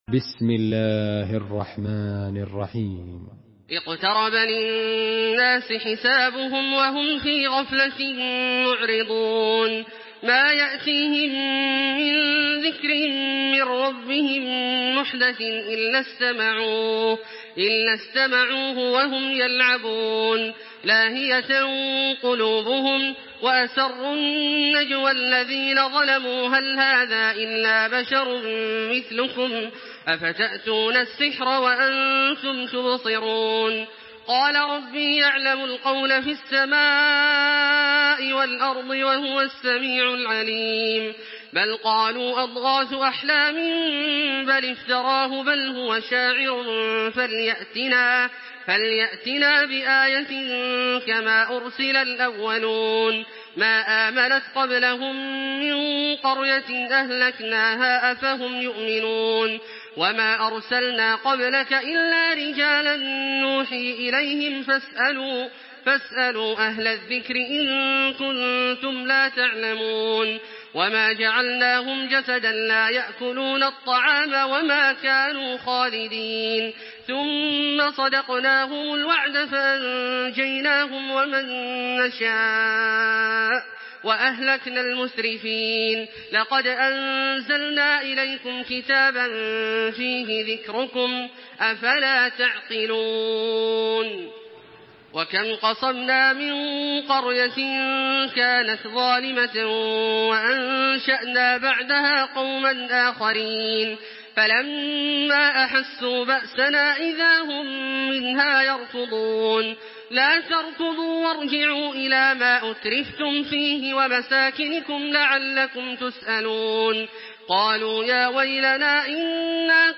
تراويح الحرم المكي 1426
مرتل